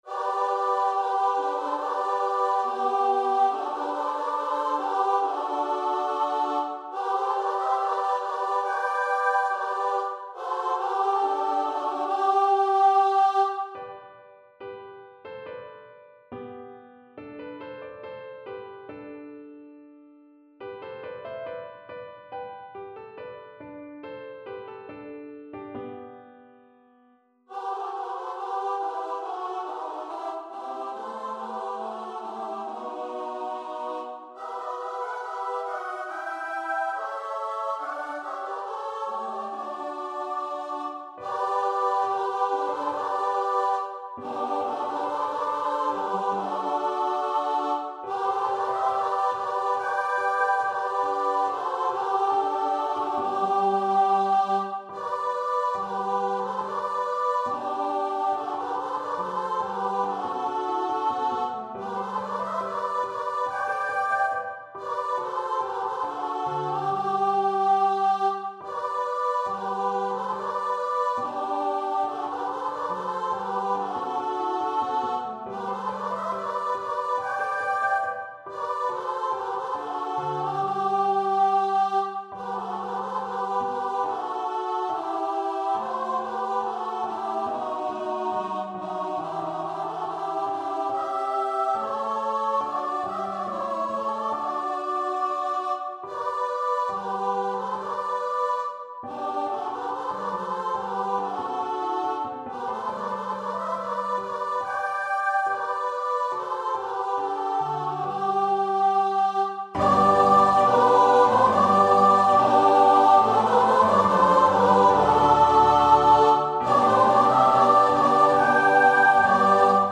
See the conqu'ring hero comes (Joshua) Choir version
Free Sheet music for Choir
Choir  (View more Intermediate Choir Music)
Classical (View more Classical Choir Music)